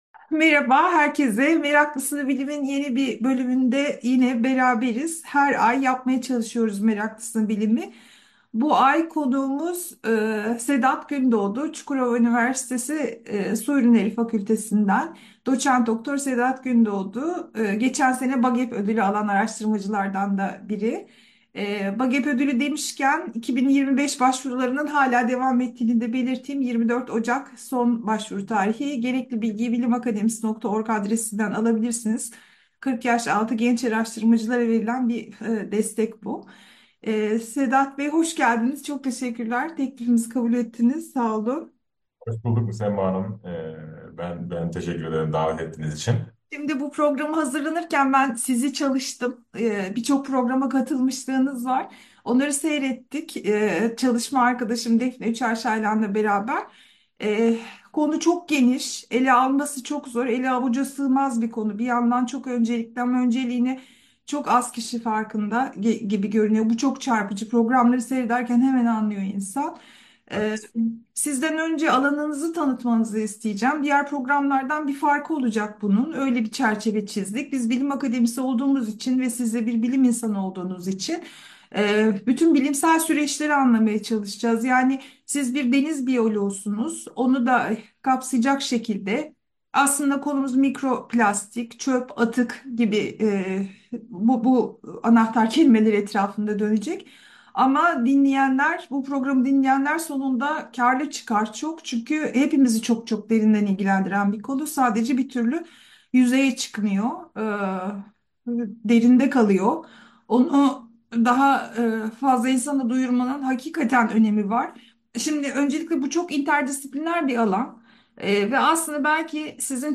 “Meraklısına Bilim” söyleşisinde bir araştırma alanı olarak mikroplastikleri konuştuk.